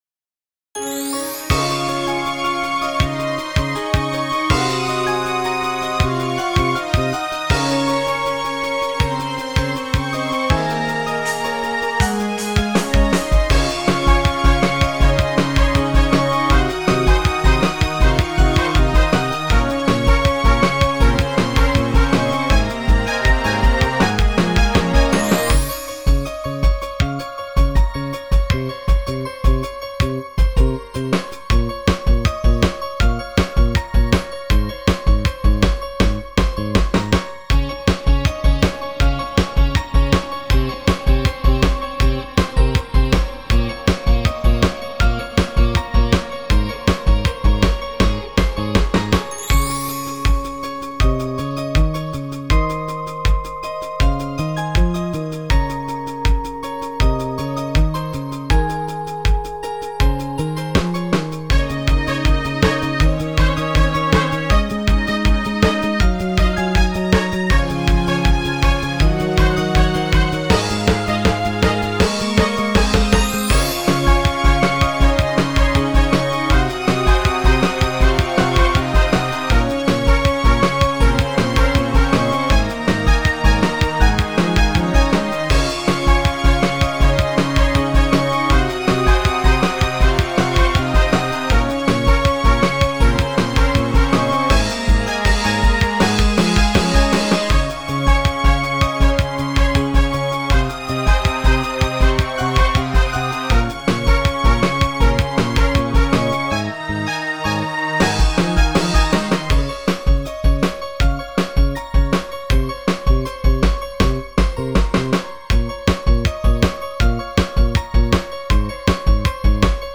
BGM
インストゥルメンタルポップロング